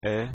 Давайте прослушаем произношение этих звуков:
é (открытое “e”):